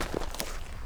pedology_gravel_footstep.1.ogg